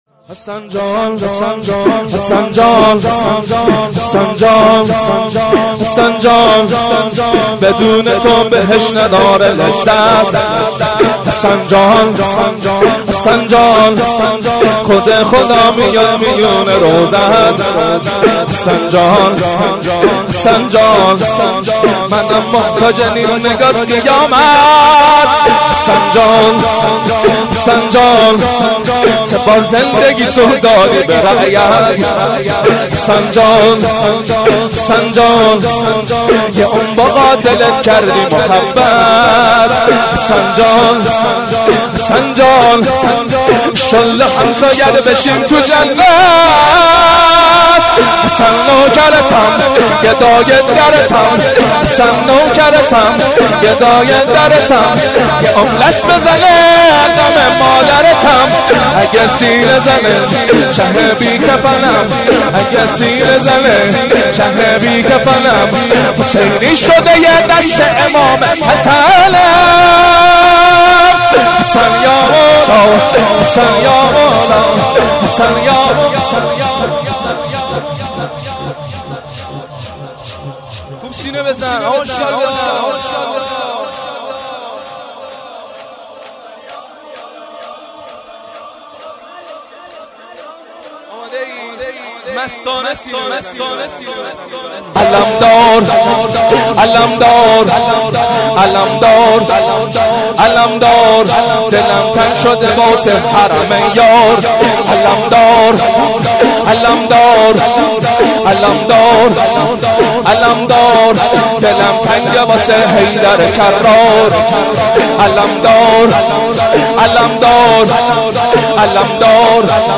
0 0 شور
استقبال از فاطمیه